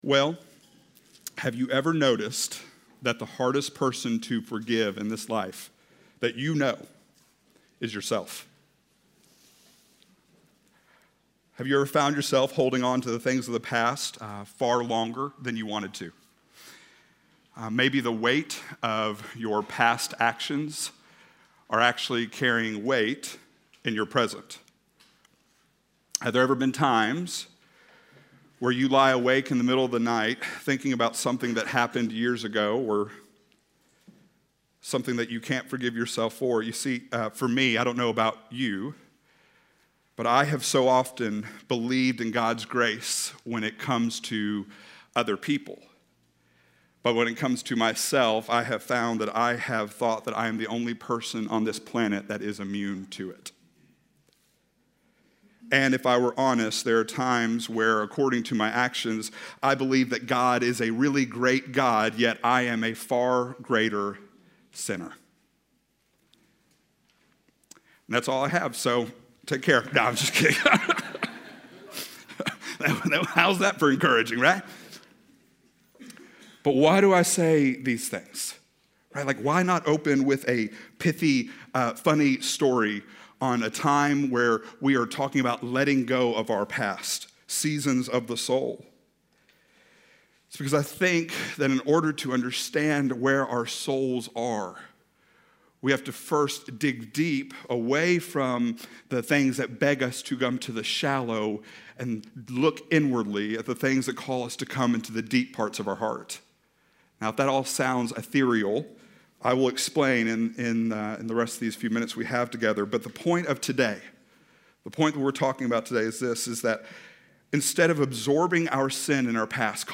Letting Go of The Past - Sermon - Avenue South